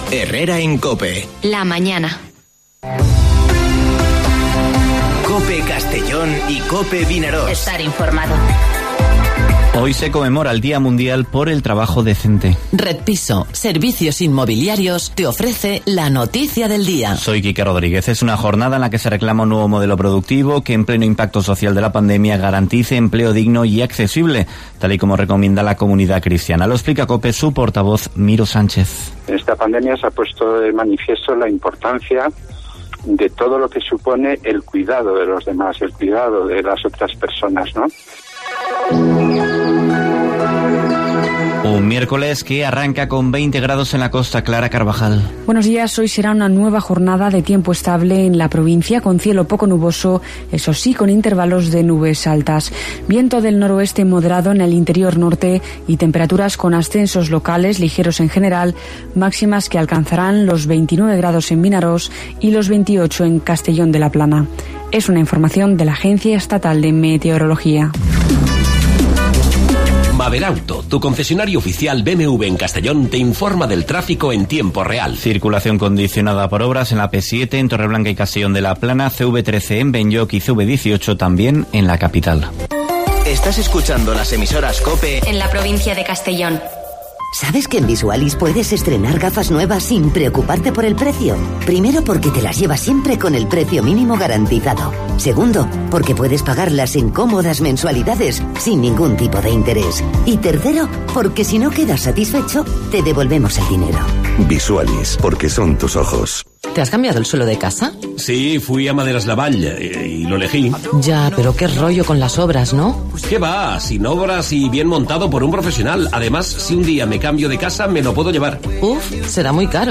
Informativo Herrera en COPE en la provincia de Castellón (07/10/2020)